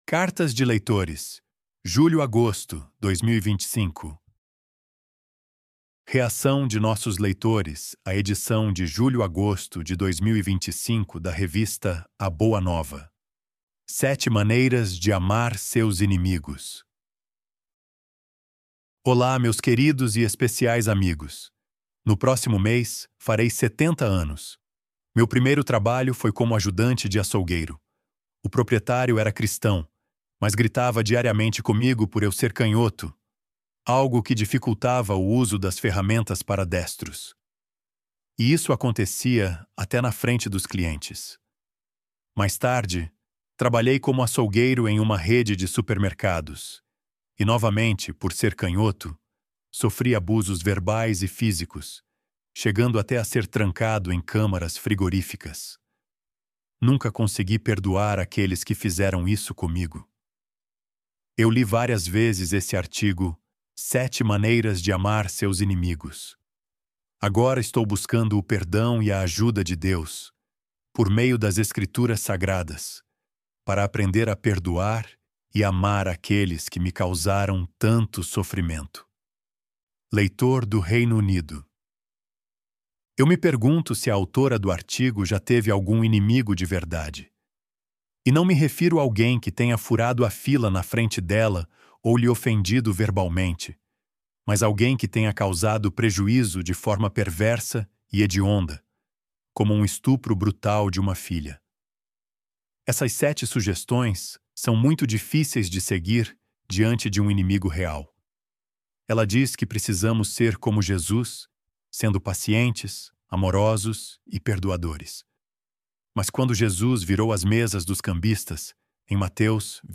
Loading the Elevenlabs Text to Speech AudioNative Player...